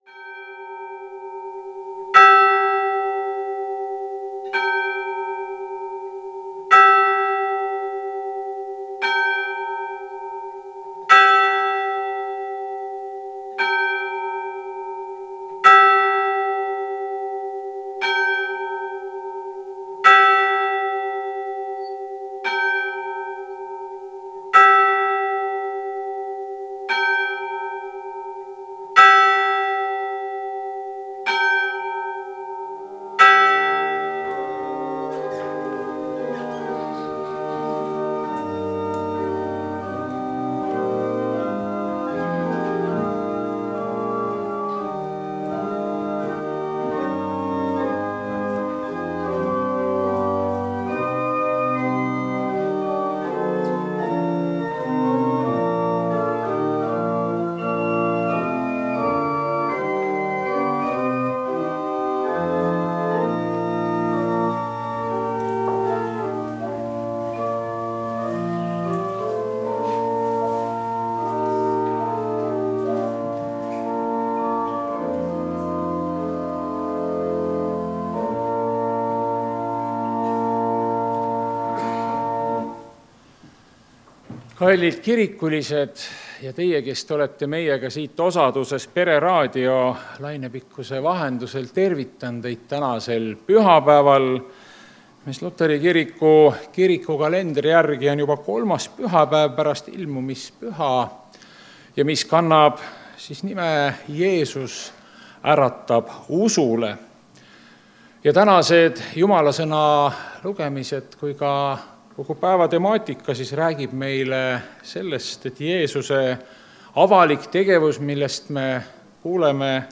Jumalateenistus 25. jaanuar 2026